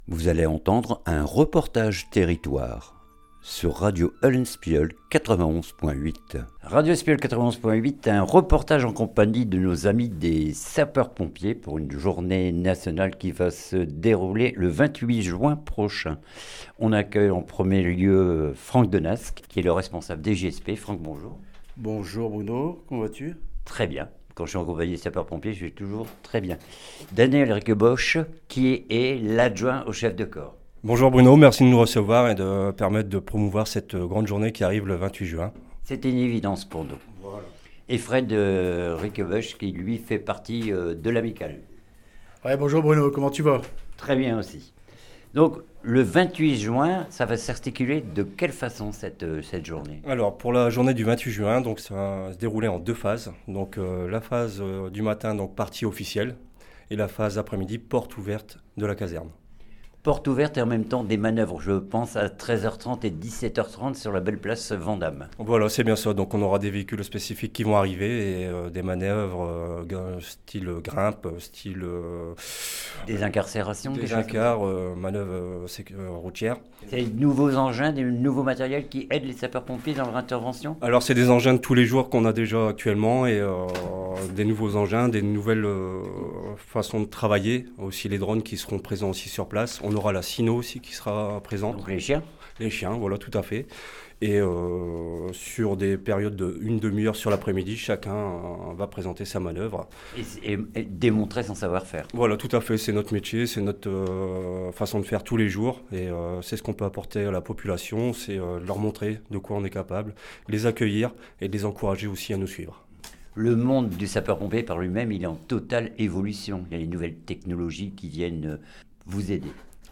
REPORTAGE TERRITOIRE JOURNEE SAPEURS POMPIERS 28 JUIN CASSEL